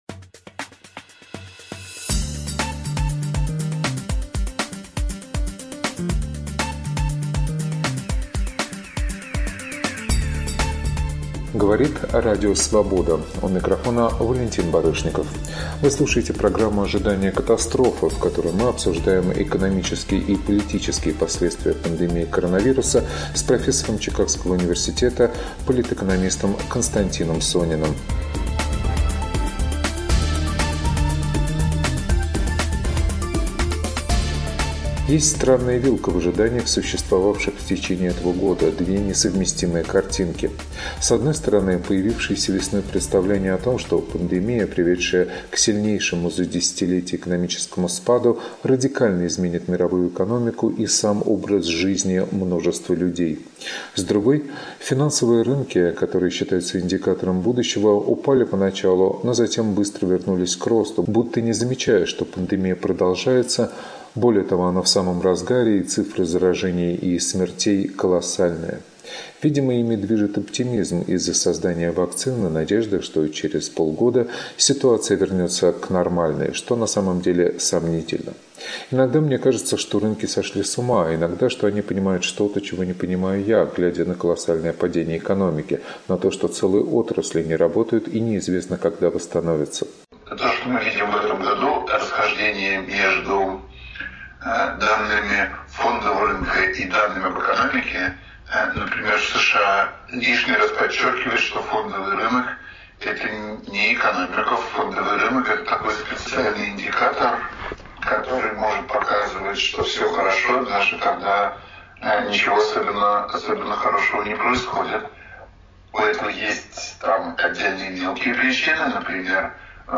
“Ожидание катастрофы”. Интервью с экономистом Константином Сониным